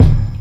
Kick 9.wav